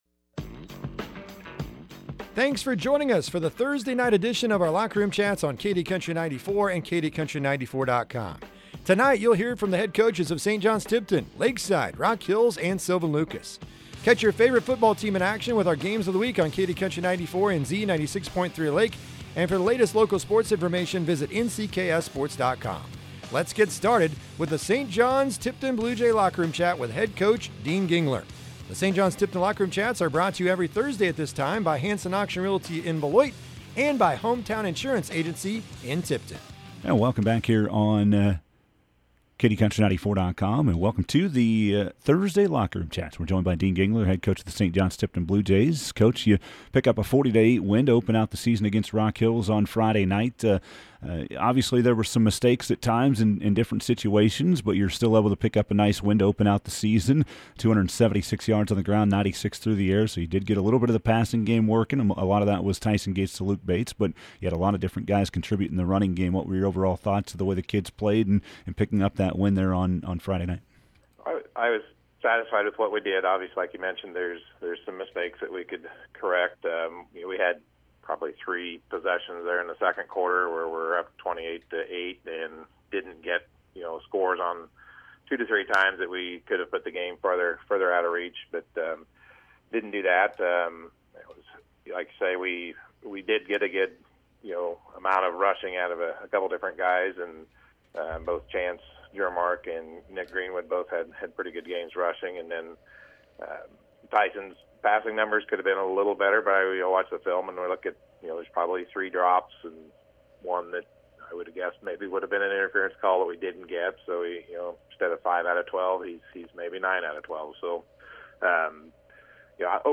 chat with the head football coaches